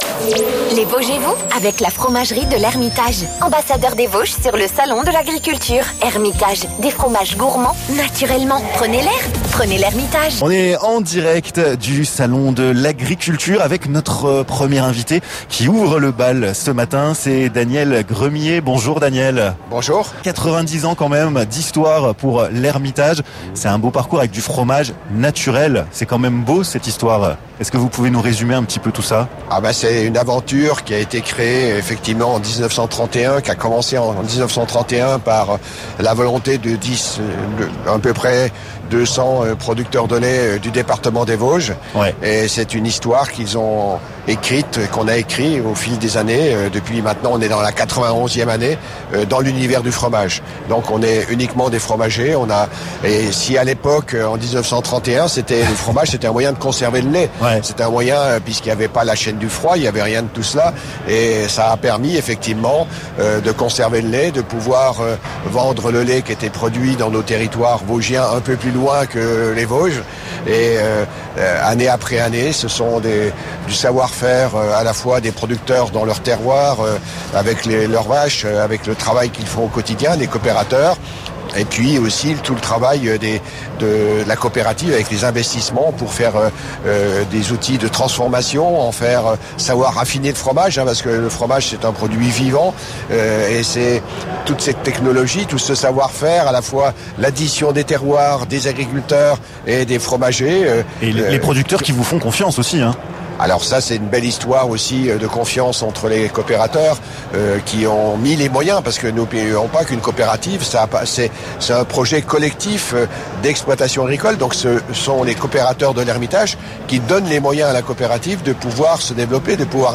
Nous vous proposons de revivre sa première émission sur place avec la fromagerie L'Ermitage !